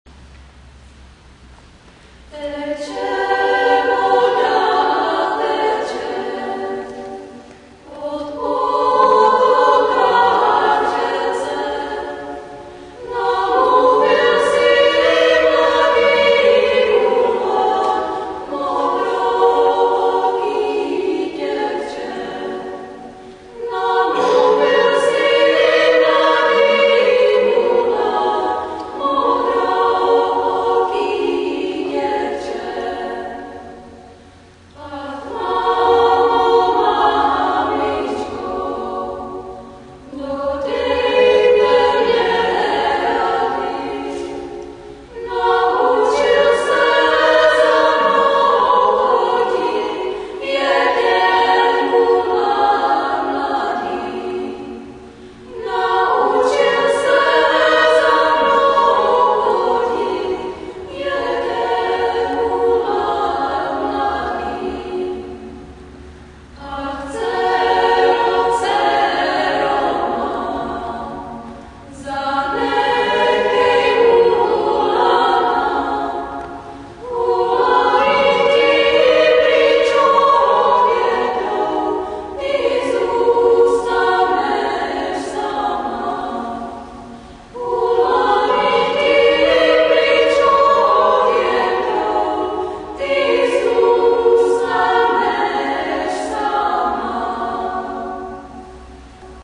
Zdraví vás smíšený pěvecký sbor Praeputium.
Ukázky z koncertu (WAV):
Teče voda (lidová) – ženy